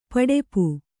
♪ paḍepu